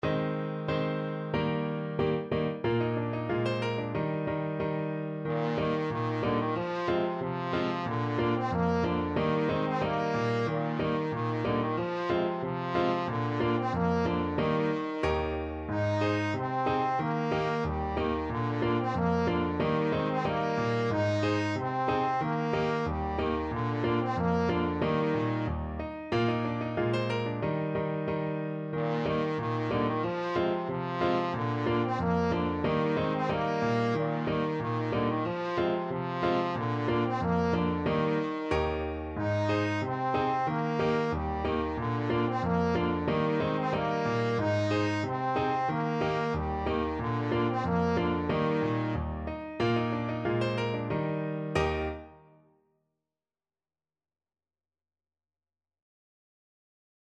Trombone version
Jolly =c.92
2/2 (View more 2/2 Music)
Trombone  (View more Easy Trombone Music)
Swiss